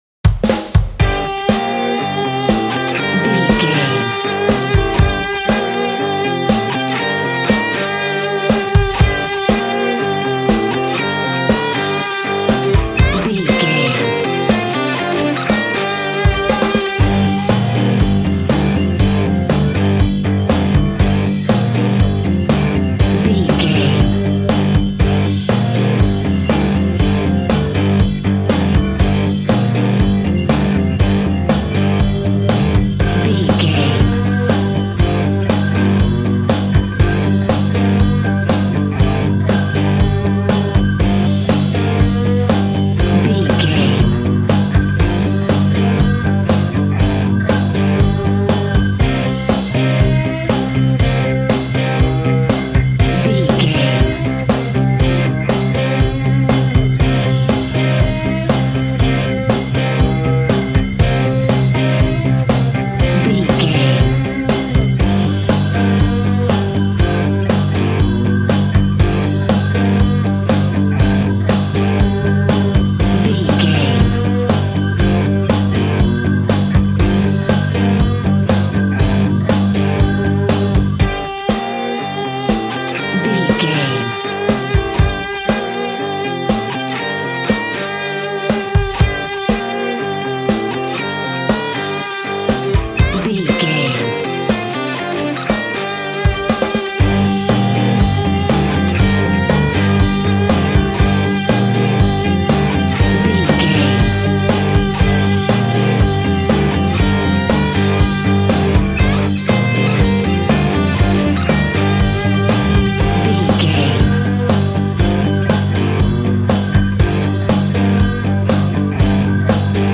Aeolian/Minor
happy
electric guitar
bass guitar
drums
piano
organ